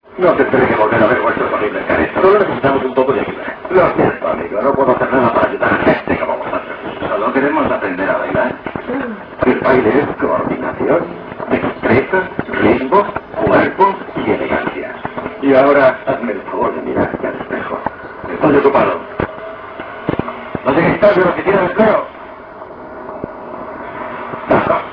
VOCES DE LA PELÍCULA